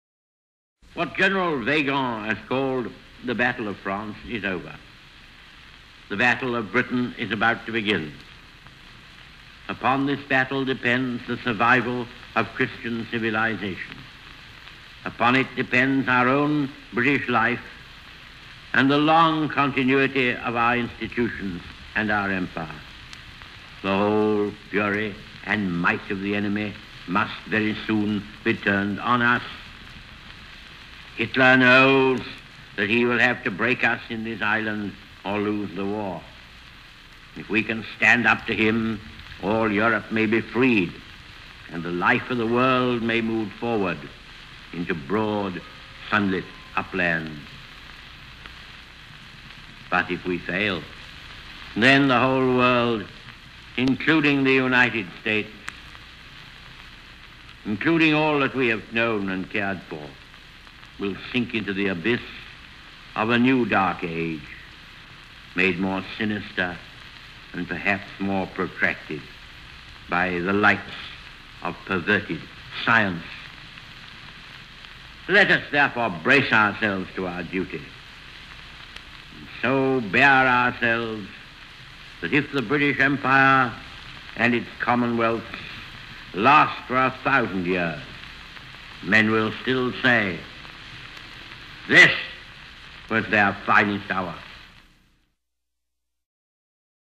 The Famous Wartime Speeches of Winston Churchill
Probably Churchill’s finest speech.  France has just capitulated and Churchill tells the British of the dire situation that now faces them as the only democracy left in arms against Germany and he predicts the coming Battle of Britain.